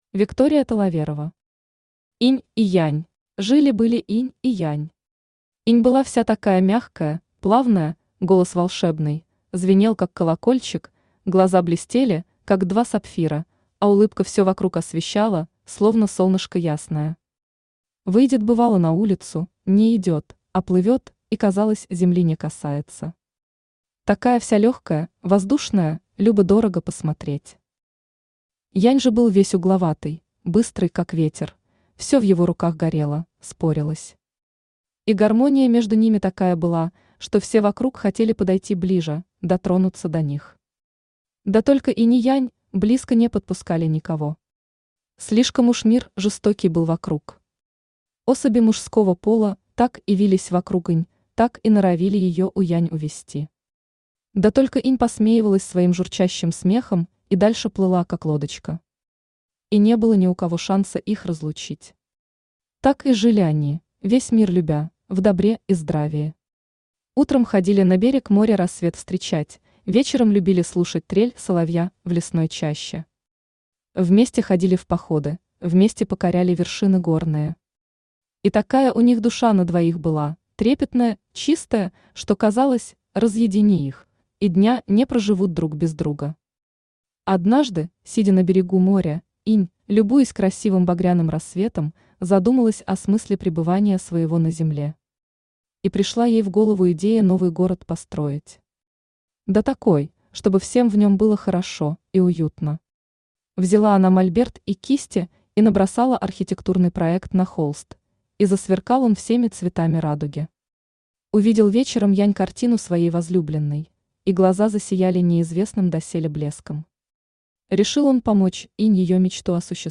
Аудиокнига Инь и Янь | Библиотека аудиокниг
Aудиокнига Инь и Янь Автор Виктория Владимировна Таловерова Читает аудиокнигу Авточтец ЛитРес.